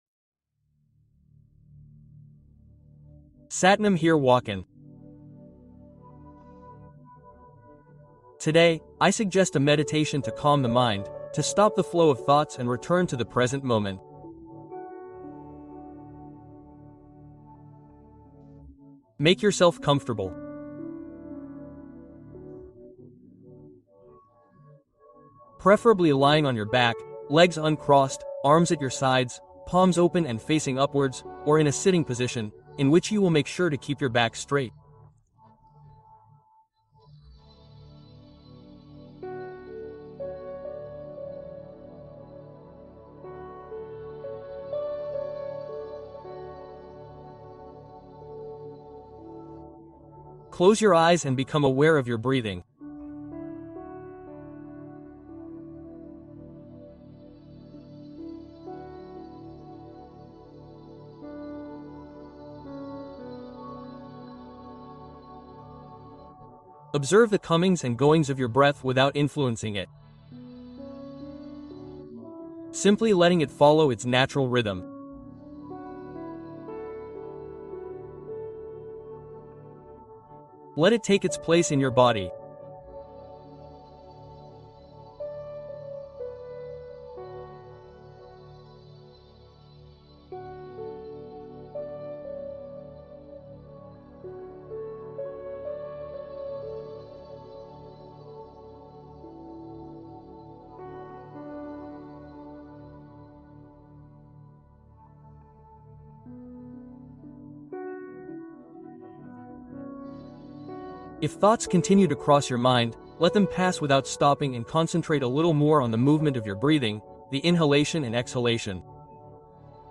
Rituel du Soir : La méditation de référence pour un lâcher-prise total